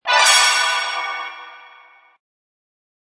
Descarga de Sonidos mp3 Gratis: magia 9.